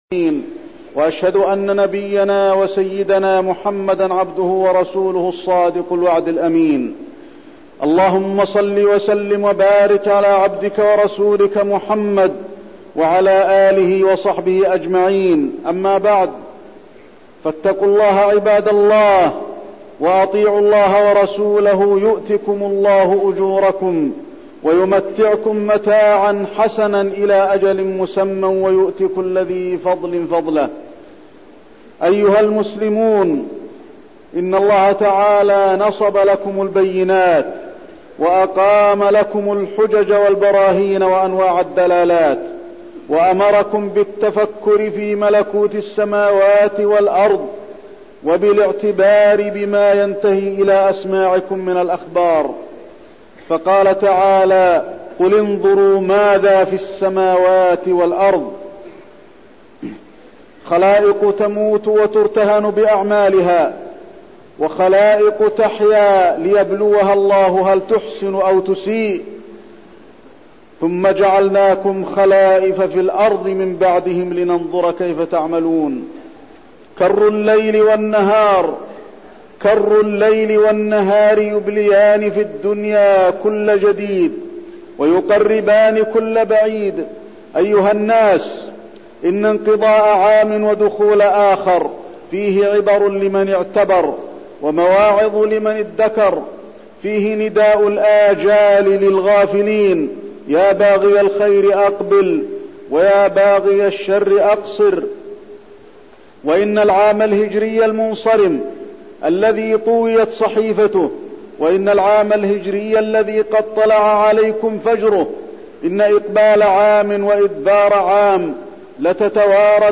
تاريخ النشر ٥ محرم ١٤١١ هـ المكان: المسجد النبوي الشيخ: فضيلة الشيخ د. علي بن عبدالرحمن الحذيفي فضيلة الشيخ د. علي بن عبدالرحمن الحذيفي دروس من الهجرة